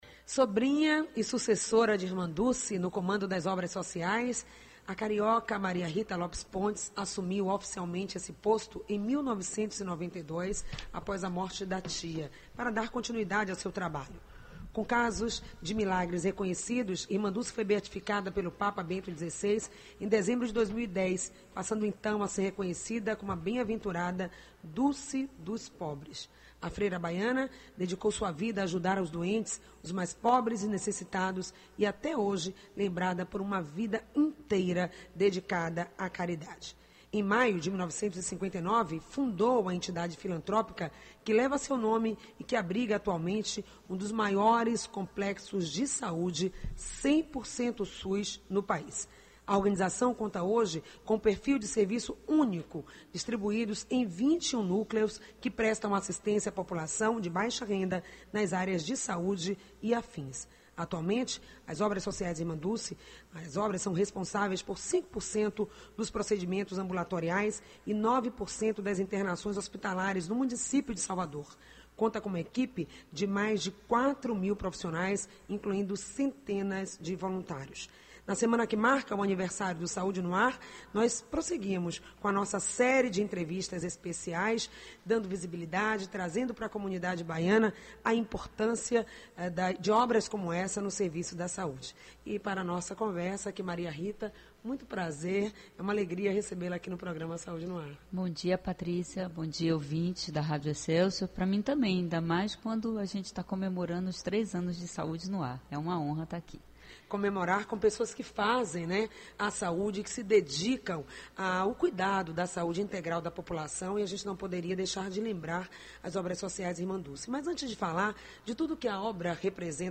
Sintonize seu rádio às 8h na Rede Excelsior de Comunicação AM840 e partipe.